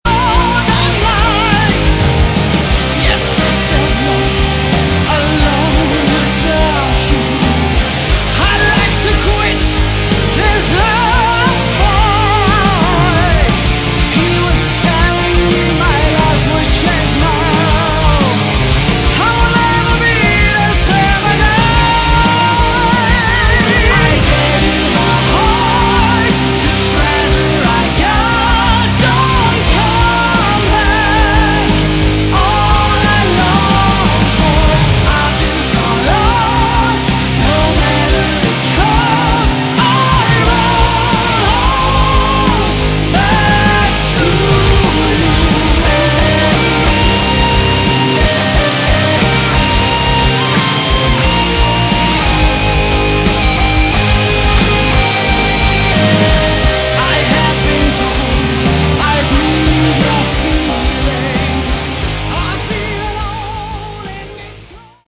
Category: Prog Rock/Metal
vocals
drums
guitar, bass, keyboards